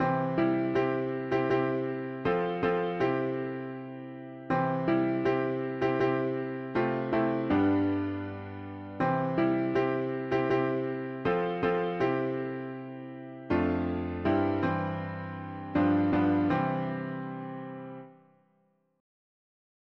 Music: African American spiritual Key: C major